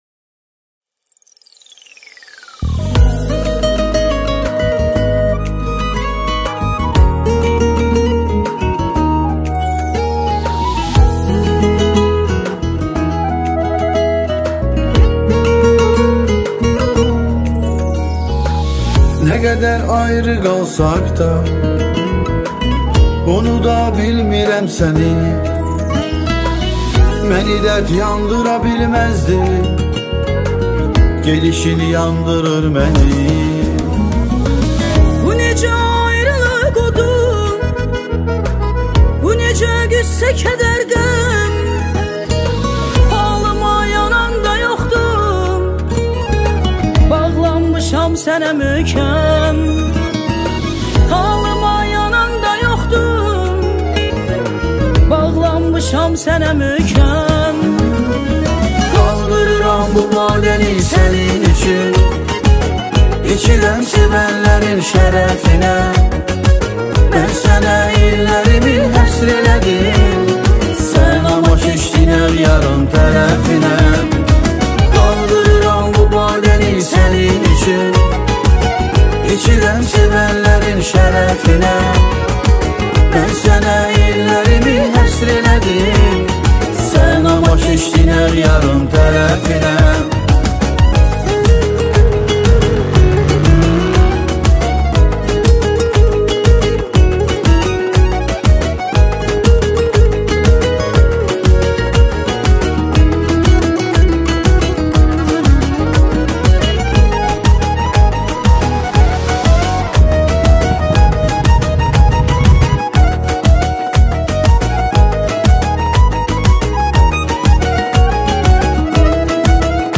• Жанр: Турецкая музыка